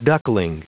Prononciation du mot duckling en anglais (fichier audio)
Prononciation du mot : duckling